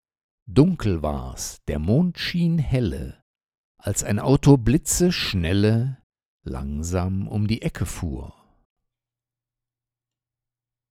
Unbearbeitete Aufnahme